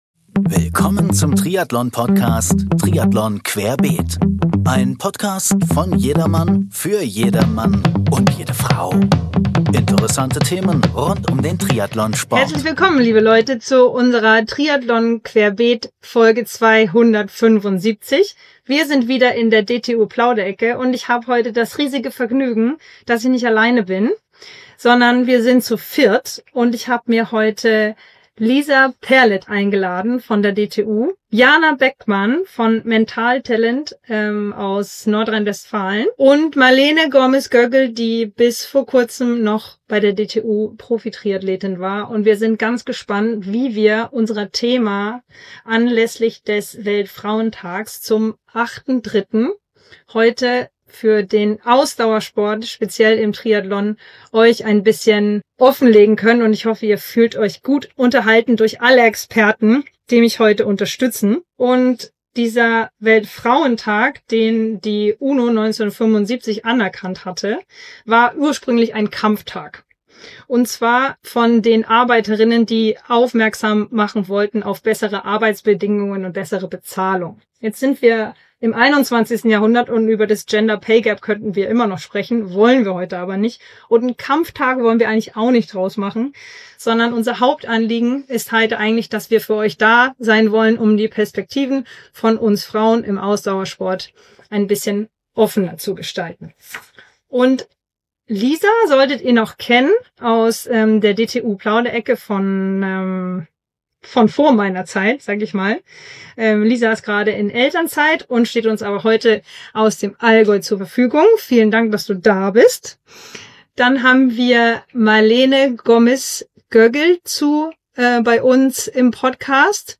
In dieser Folge sind wir zu viert – eine echte Jederfrau-Premiere bei TQ: ein Podcast von und für Frauen. Anlässlich des Weltfrauentags am 08. März 2026 sprechen wir mit vier beeindruckenden Powerfrauen aus dem Ausdauersport.